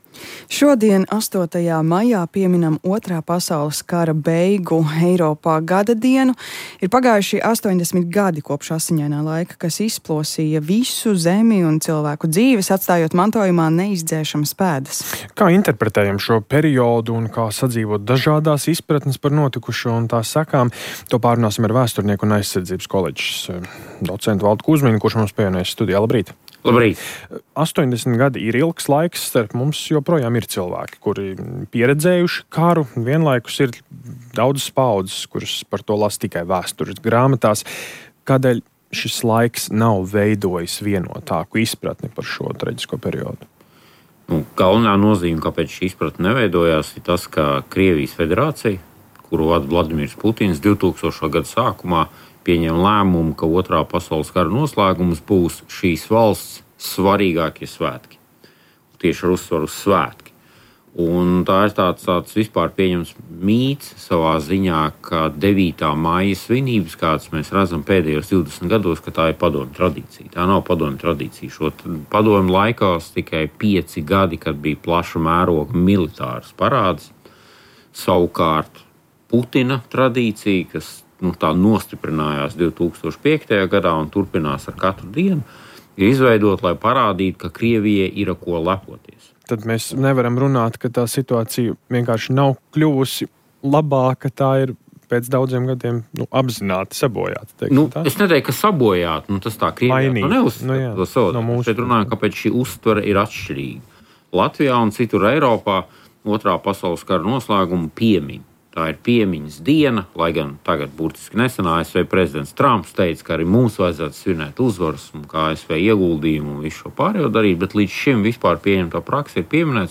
Pētnieks: Prezidenta vēlēšanas ASV nav par ārpolitiku, bet par ikdienu – Rīta intervija – Podcast